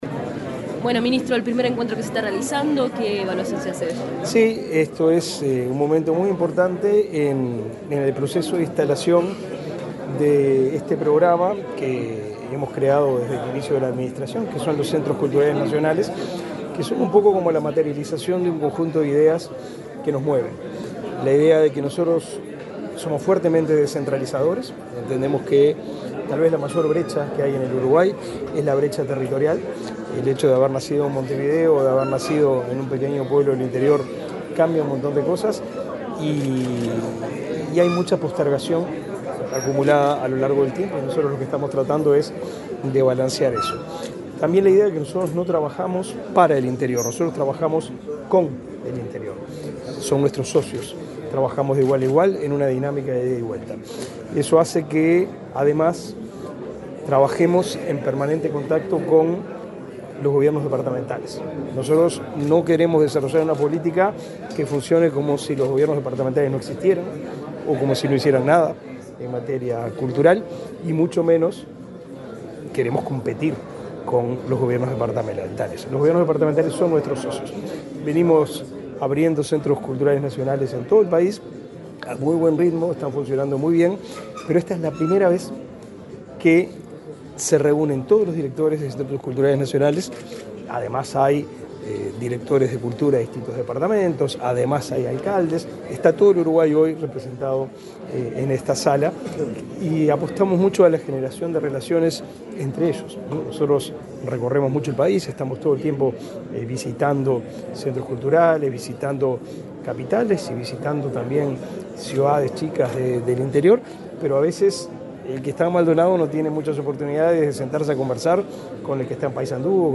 Declaraciones del ministro de Educación y Cultura, Pablo da Silveira
Declaraciones del ministro de Educación y Cultura, Pablo da Silveira 04/04/2024 Compartir Facebook Twitter Copiar enlace WhatsApp LinkedIn El ministro de Educación y Cultura, Pablo da Silveira, fue entrevistado por medios periodísticos, luego de participar, este jueves 4 en Montevideo, en la apertura del Primer Encuentro de Centros Culturales Nacionales.